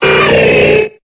Cri de Tygnon dans Pokémon Épée et Bouclier.